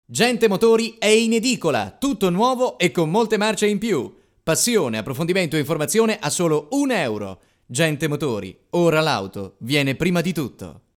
Voce frizzante, importante, per promo, spot radio e tv, trailers, cortometraggi, redazionali, messaggi promozionali, voice over, station id e tanto altro
Sprechprobe: Werbung (Muttersprache):
I'm a very important speaker all over Italy...my voice is hot, young and versatile for any kind of production